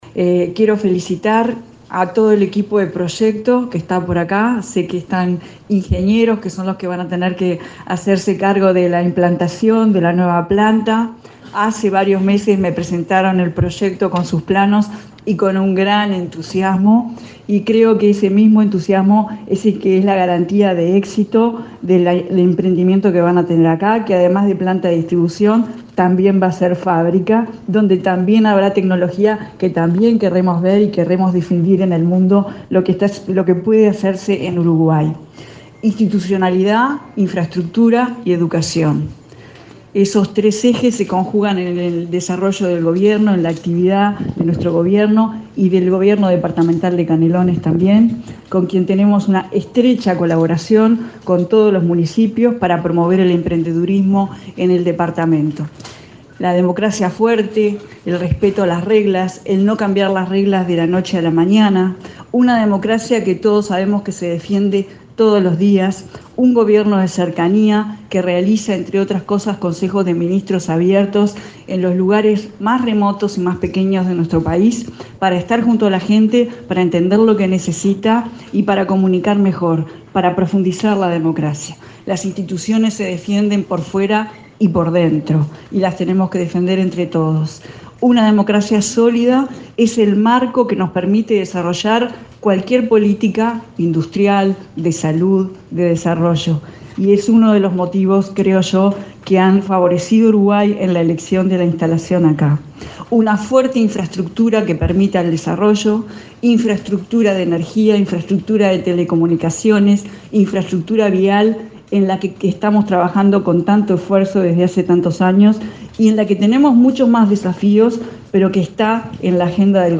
Institucionalidad, infraestructura y educación, esos tres ejes se conjugan en la actividad del Gobierno, afirmó la ministra de Industria, Carolina Cosse. “Una democracia sólida y una fuerte infraestructura nos permiten desarrollar cualquier política industrial”, sostuvo durante la colocación de la piedra fundacional de la nueva planta de Nestlé ubicada en el Parque Industrial Zona Este, en el kilómetro 24,200 de la ruta 101.